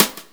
just SNARES 3
rimshotldk02.wav